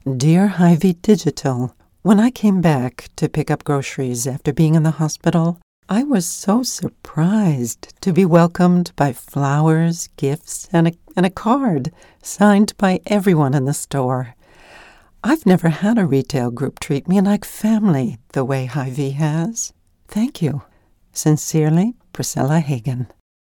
Female
Corporate
Letter From Senior Customer
All our voice actors have professional broadcast quality recording studios.